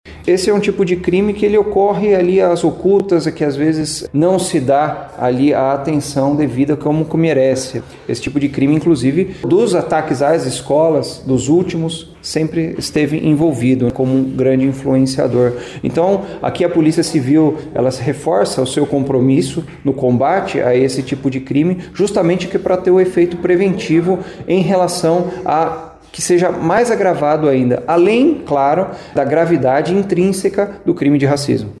Esse tipo de crime, com apologia ao nazismo e ao neonazismo, ocorre de maneira velada, segundo o delegado, que ainda falou sobre algumas situações de violência em que houve o envolvimento do neonazismo.